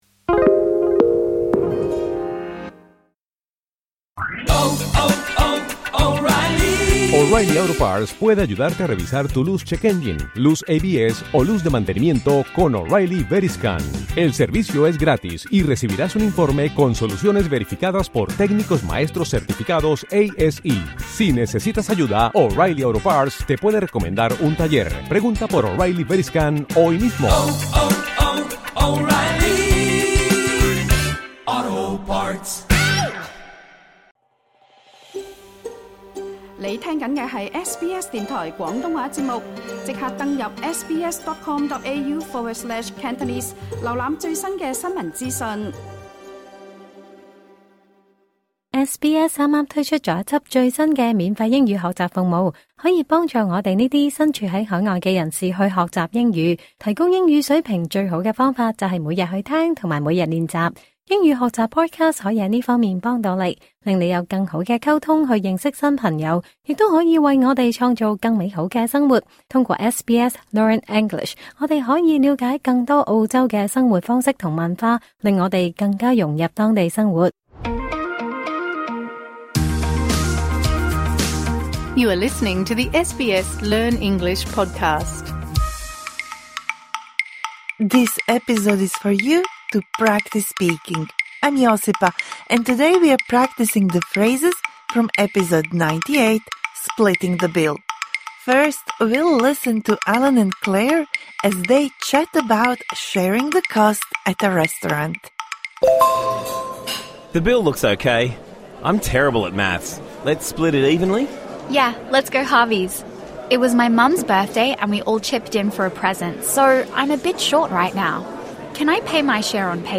大聲朗讀是其中一個讓你改善英語發音及學識新詞的最有效方法。